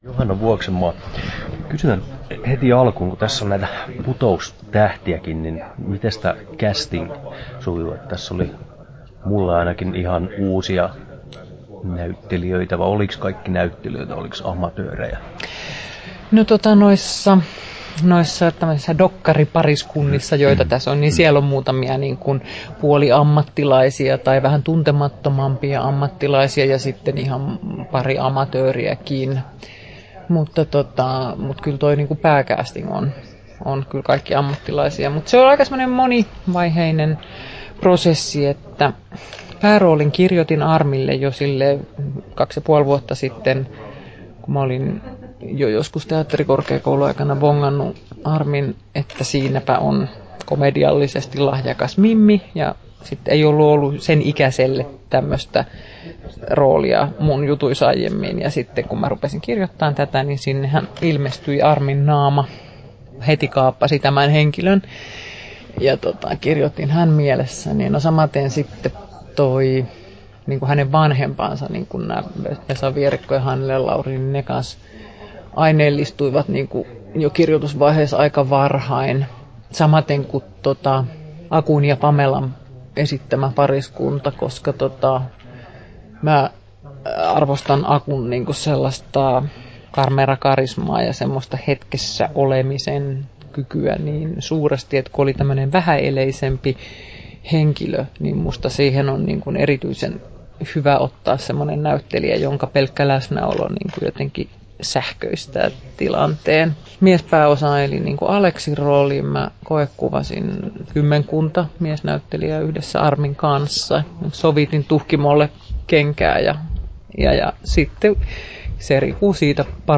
Johanna Vuoksenmaan haastattelu Kesto